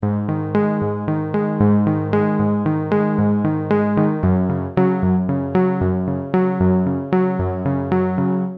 Bassline, 135 KB
tb_bassline.mp3